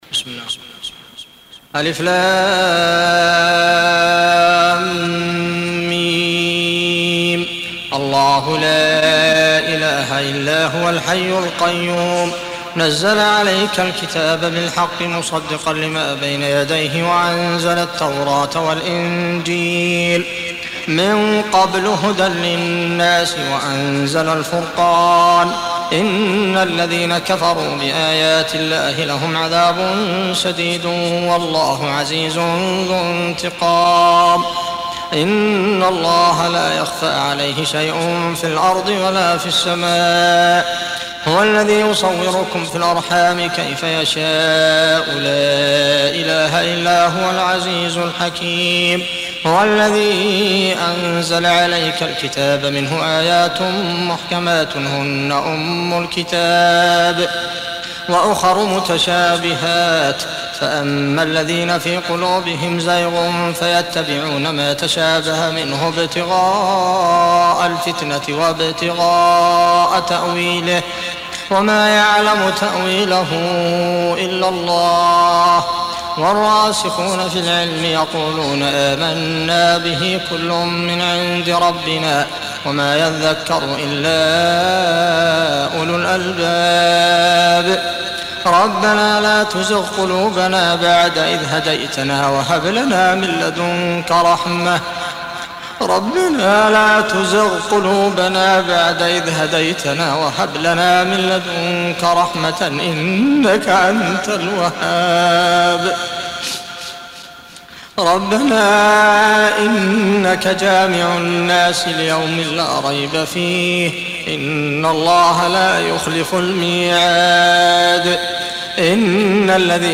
الاستماع للقرآن الكريم بصوت القارئ : عبدالمحسن العبيكان